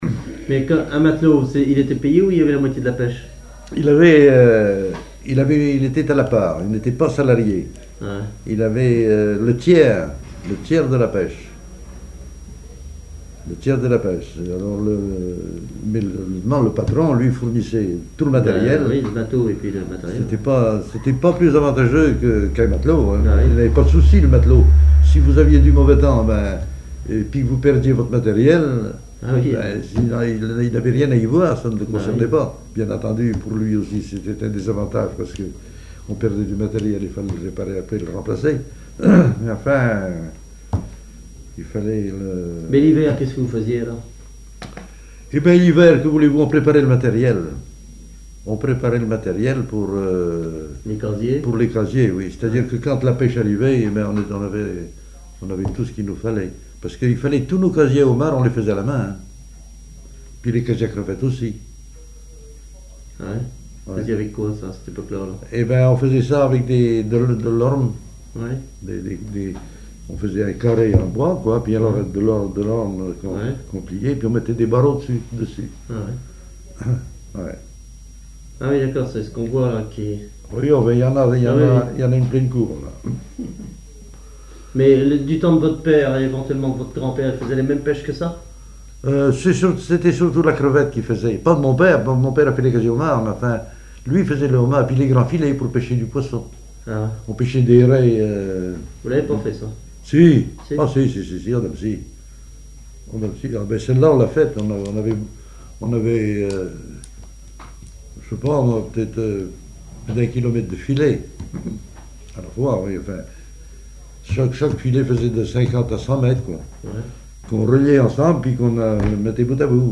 témoignages sur les activités maritimes locales
Catégorie Témoignage